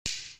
麻将掉落.MP3